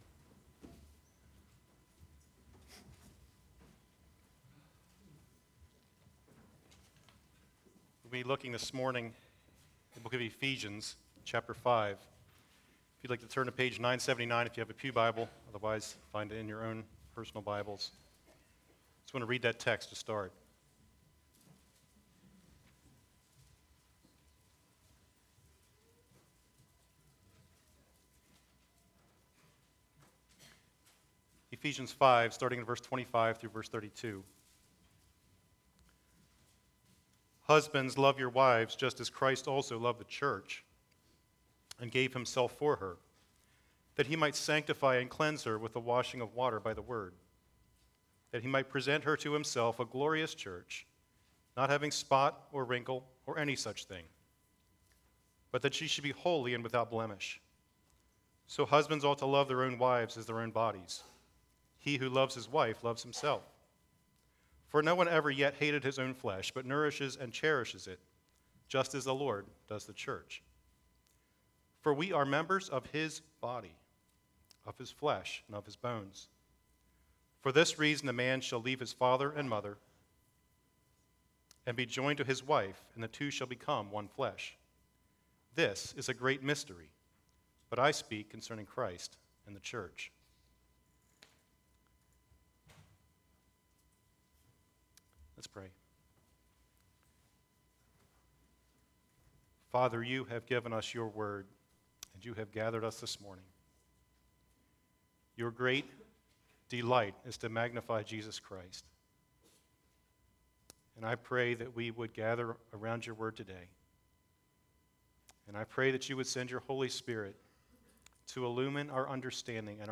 Sermons Archive - Page 60 of 90 - Calvary Bible Church - Wrightsville, PA